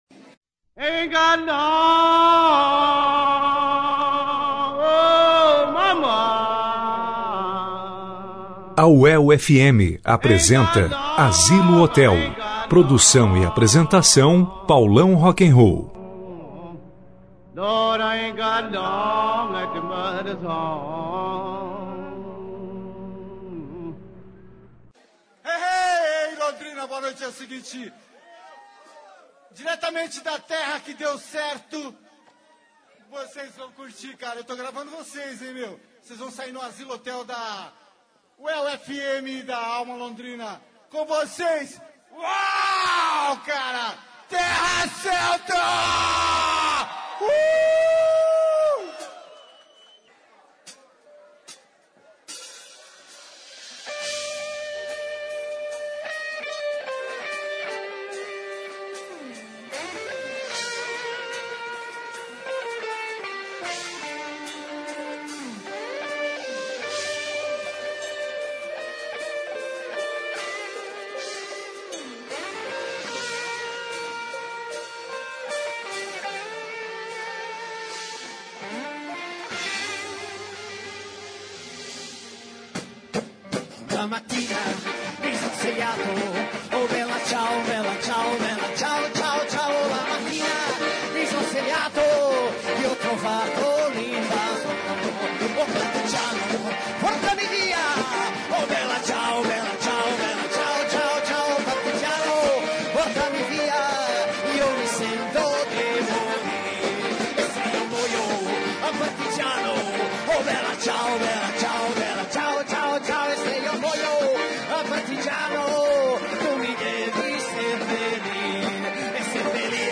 Hoje a programação apresenta uma entrevista especial para o Azylo Hotel que está em duas rádios a UEL FM e a AlmA Londrina, e as duas merecem conhecer e apresentar a seus públicos o que acontece dentro desta cerimonia pagã em que o TERRA CELTA conduz com toda sabedoria e exorcismos da tristeza.